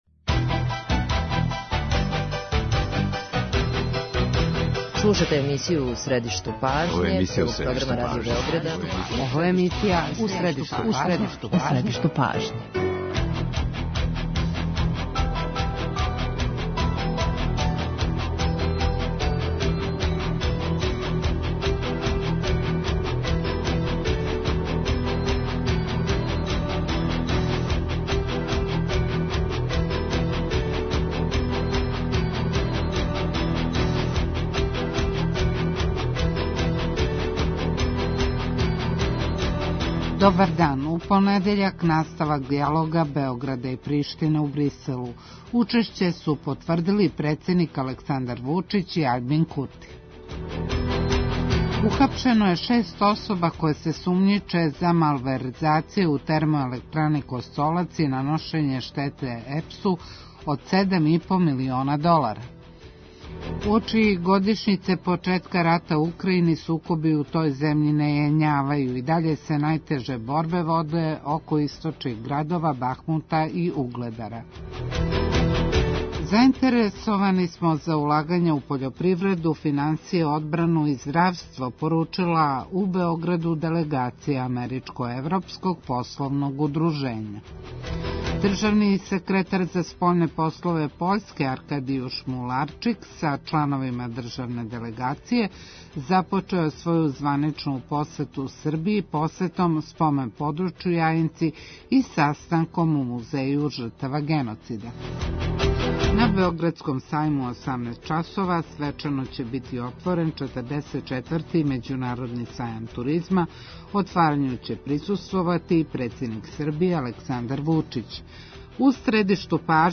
интервју
Разговор